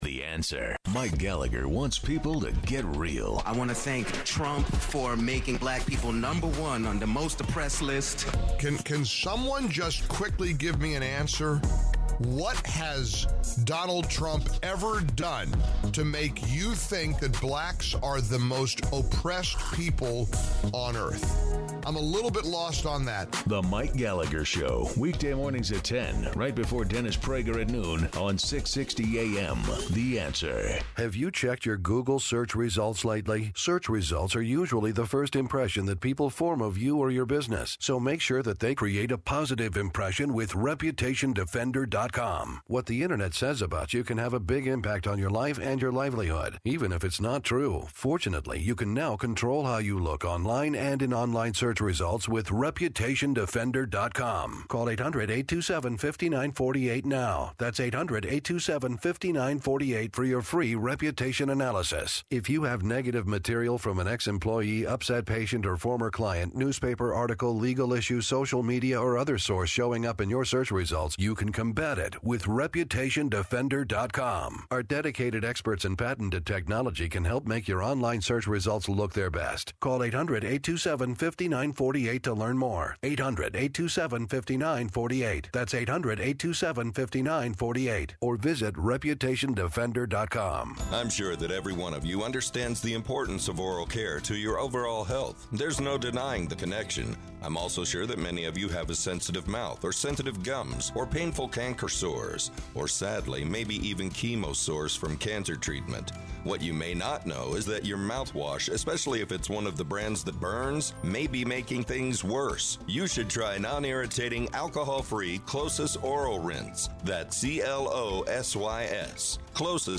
HR 2 – Political Party Fund-Raising Tells…; Millennials and Free Speech; Hillary is Clueless; Interview with Dr. Patrick Moore…a Green Peace Founder!
Listen to the second hour of our September 24th show here.